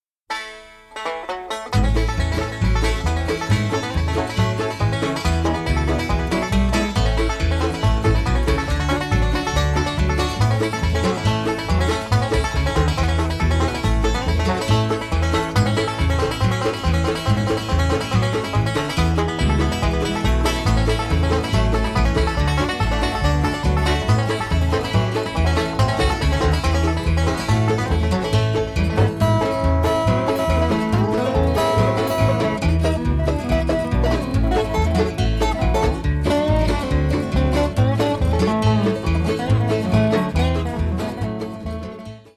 Traditional
Listen to the Mountaineers perform "Hand Me Down My Walking Cane" (mp3)